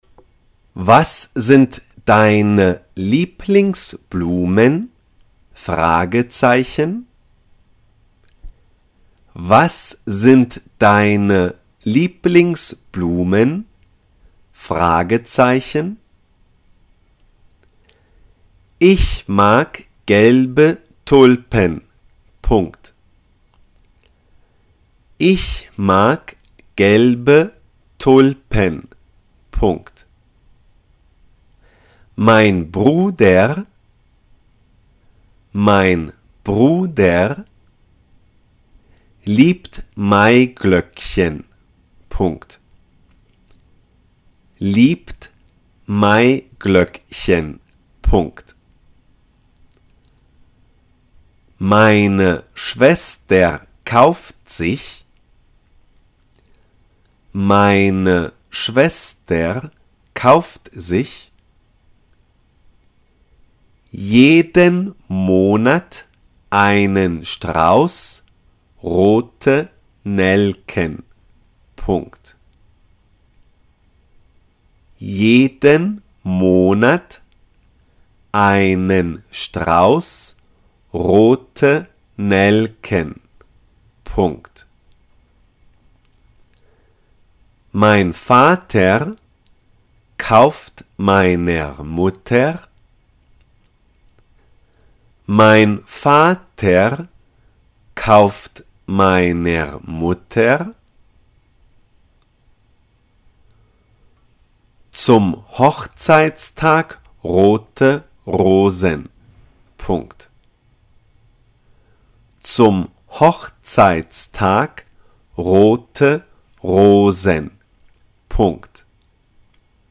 die Blumen – Las flores .dictado
Dictado-en-aleman-sobre-las-flores-die-Blumen-AprendeAlema-1.mp3